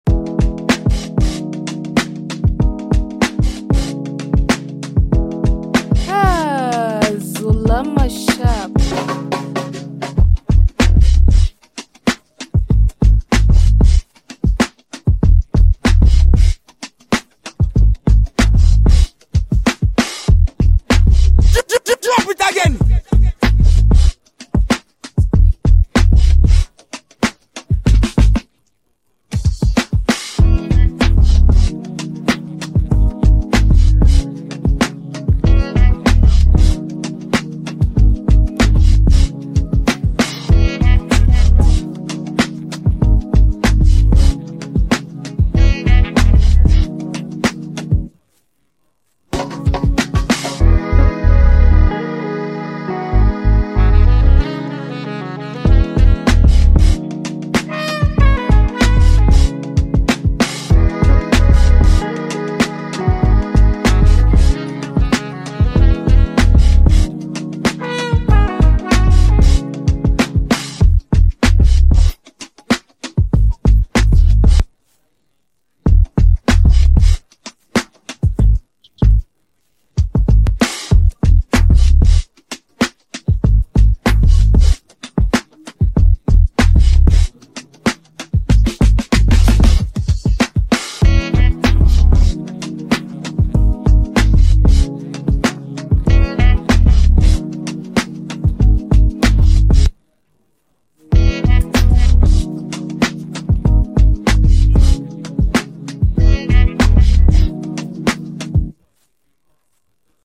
Afrobeats Amapaino